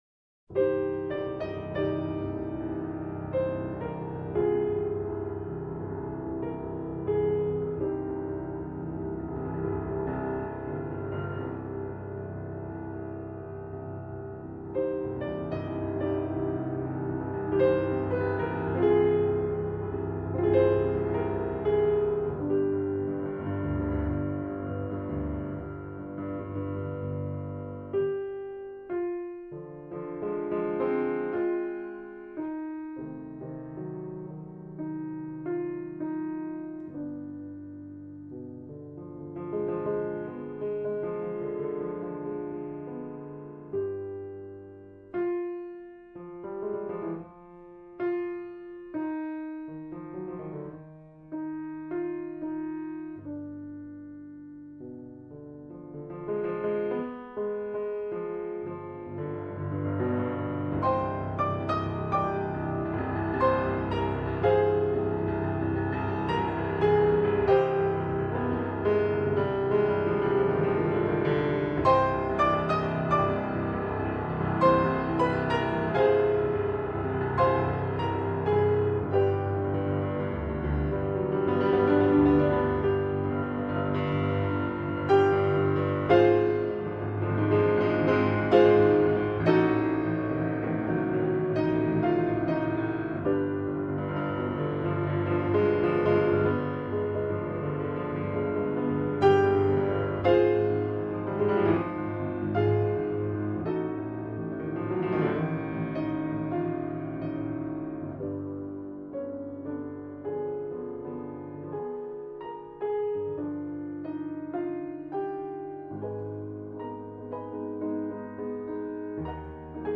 เปียโน
เพลงพระราชนิพนธ์